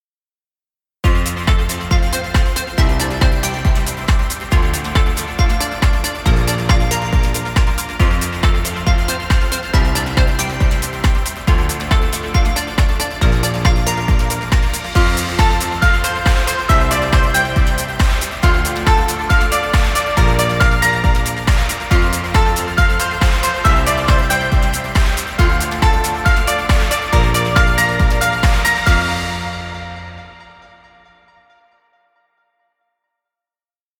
Stock Music.